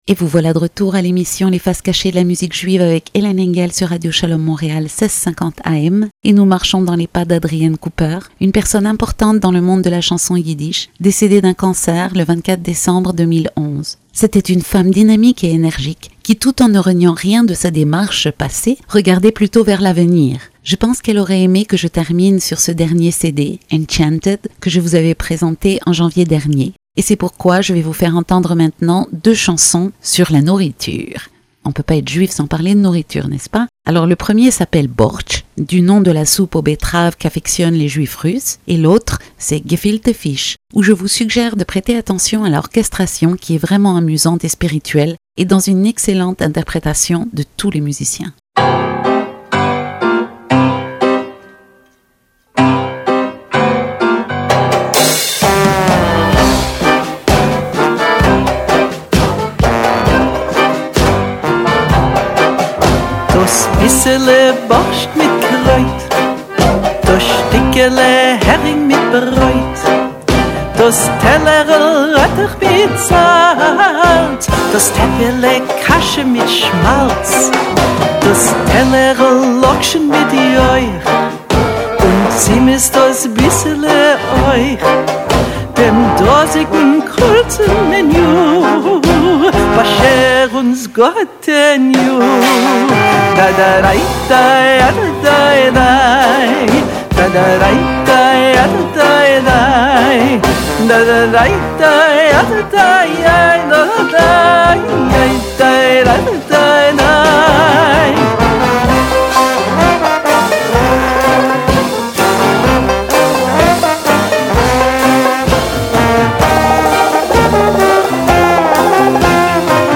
A radio show broadcasted on Radio SHALOM Montreal